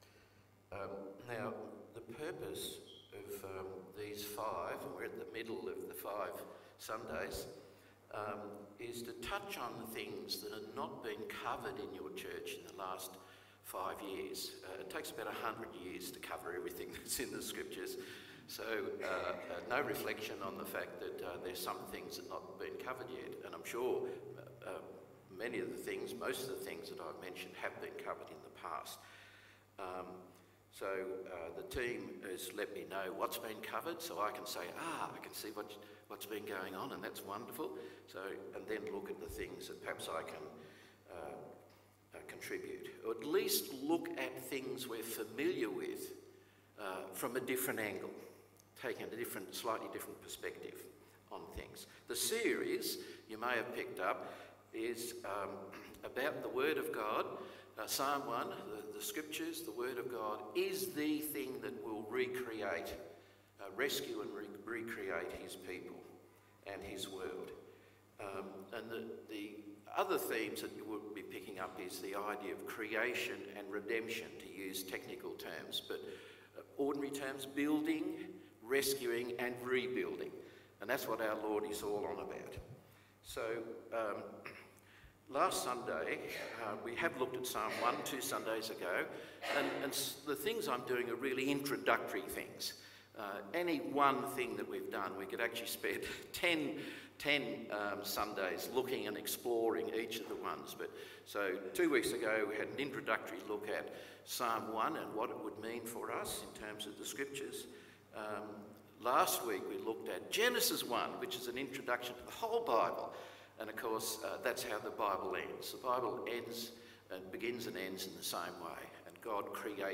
The third in a series of five sermons.
Service Type: AM Service